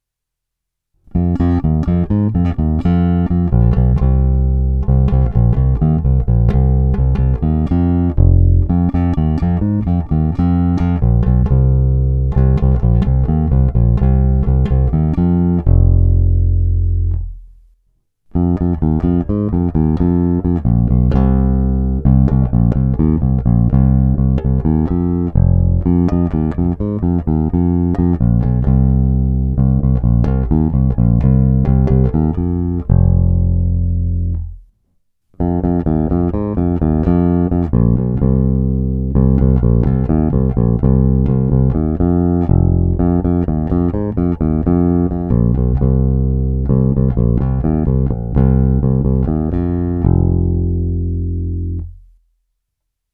Má hodně výrazné středy, je poměrně agresívní, kousavý.
Není-li uvedeno jinak, následující ukázky byly provedeny rovnou do zvukové karty a s plně otevřenou tónovou clonou, jen normalizovány, jinak ponechány bez úprav.